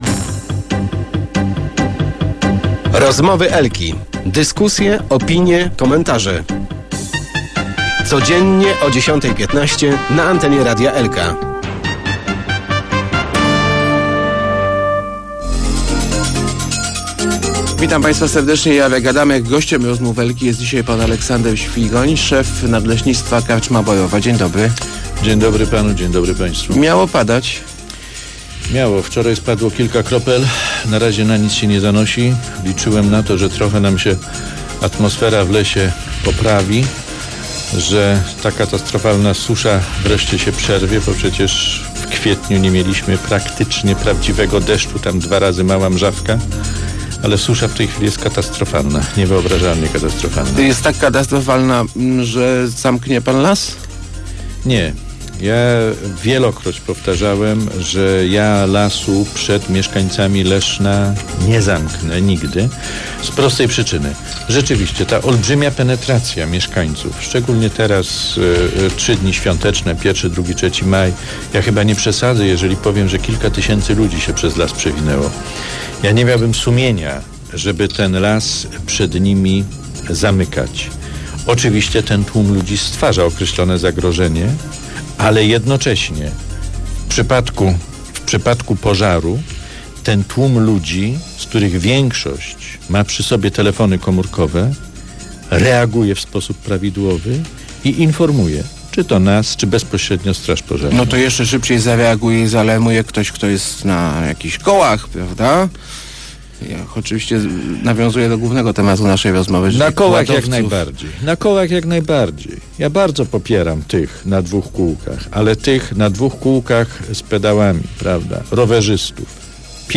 Start arrow Rozmowy Elki arrow Quady - nowa zmora leśników..